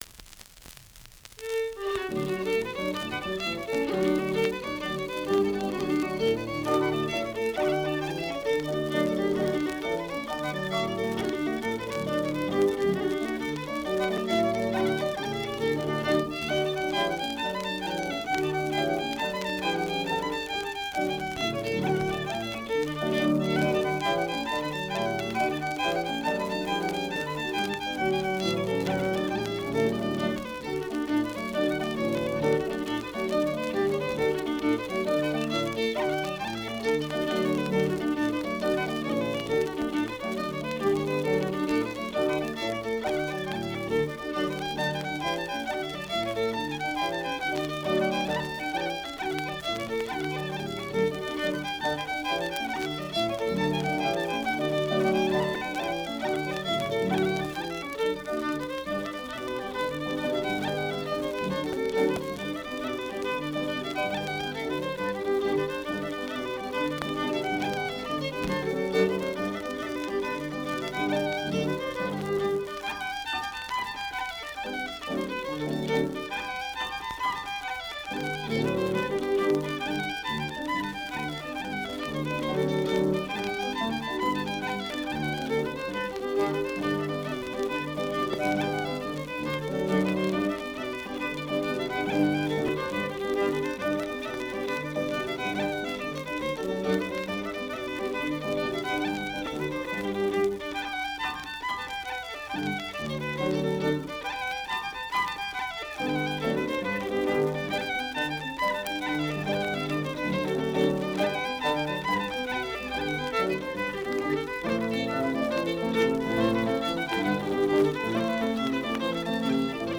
Keywords: Irish Traditional Music
Fiddle, with guitar accompaniment. digitised from an original 78RPM shellac record